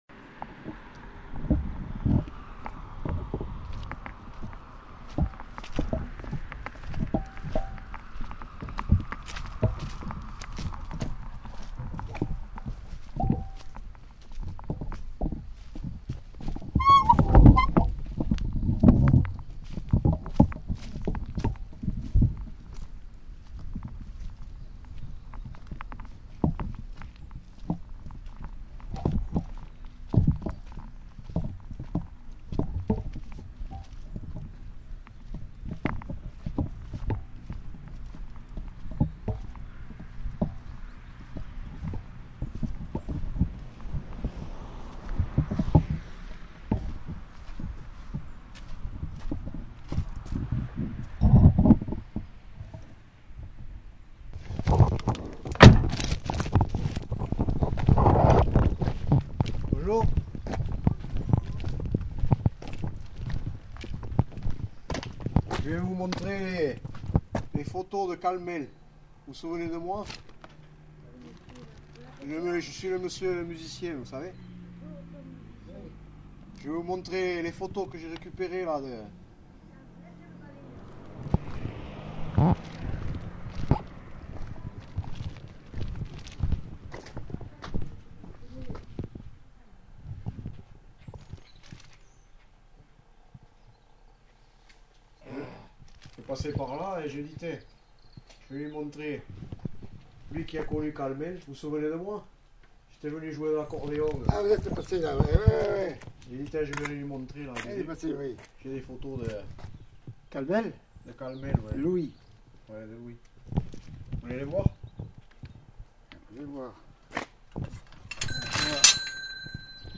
Genre : parole